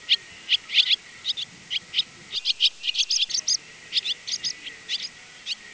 Hirundo rustica
rondine.wav